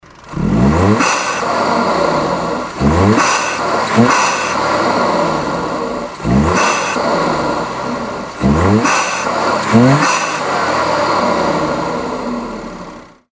シーーーーーーー！という吸気音が
ブースト圧に比例して大きくなっていきます。
吸気音のサンプル
（HKSスーパーパワーフロー＆純正マフラー）
↑ちなみにエンジンルームを開けた状態で
録音したので、実際にはこんなに音は大きくないですよ・・
wagonr-kyuki.mp3